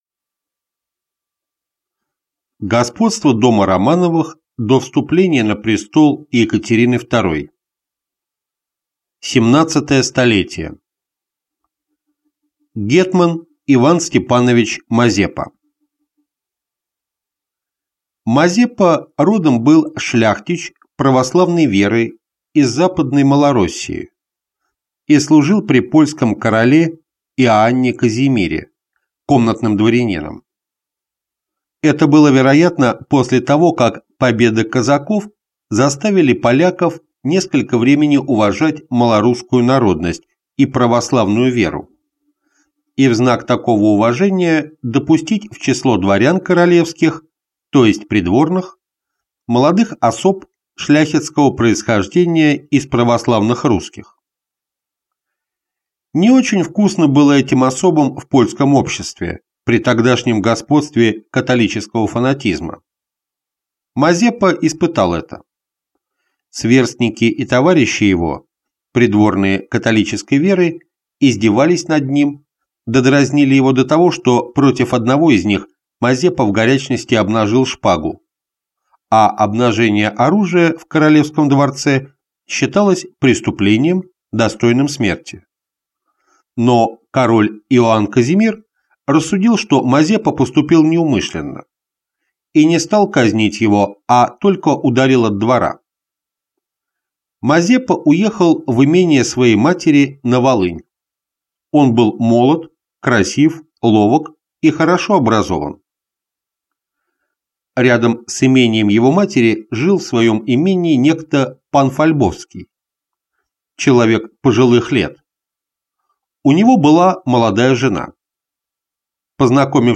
Аудиокнига Гетман Иван Степанович Мазепа | Библиотека аудиокниг